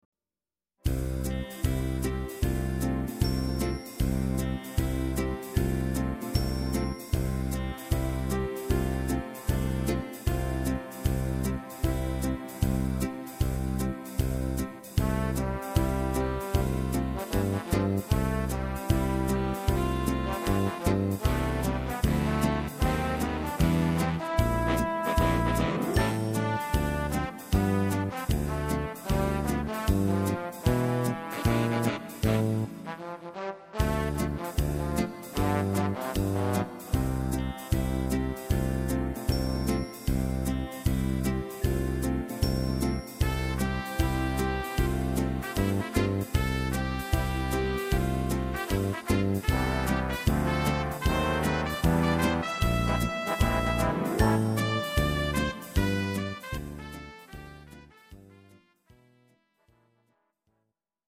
instrumental Orchester